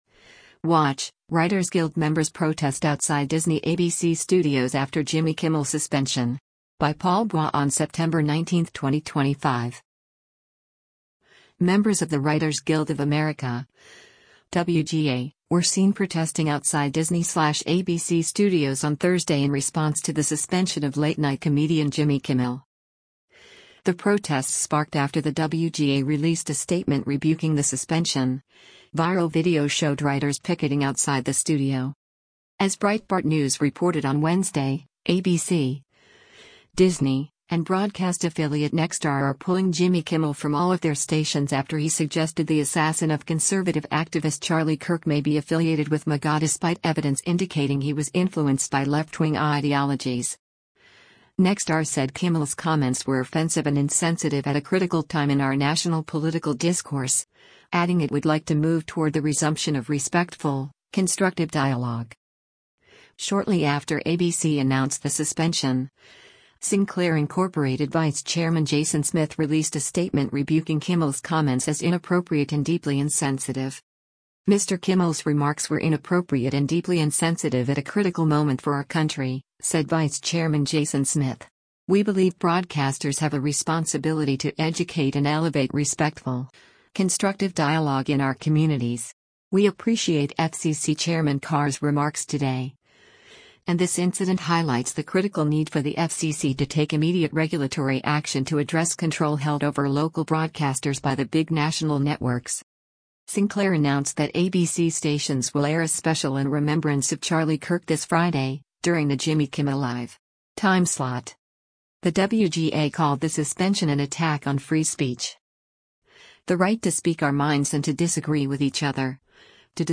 The protests sparked after the WGA released a statement rebuking the suspension; viral video showed writers picketing outside the studio.